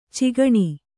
♪ cigaṇi